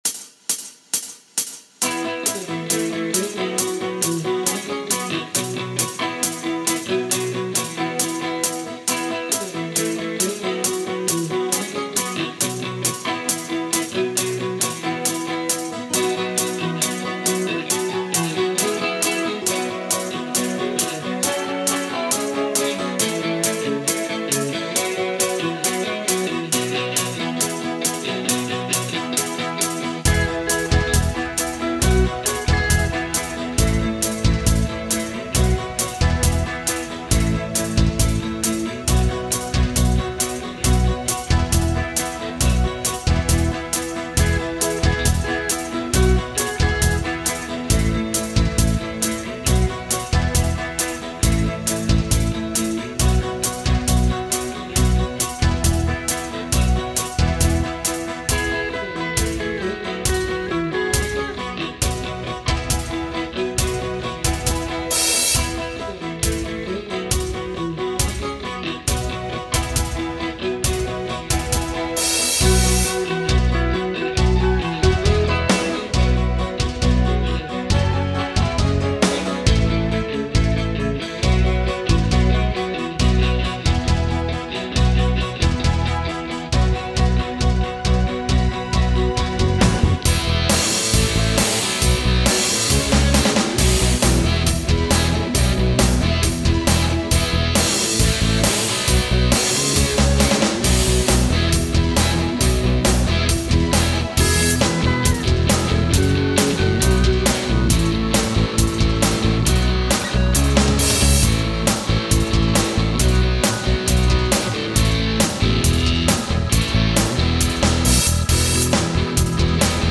Song Sample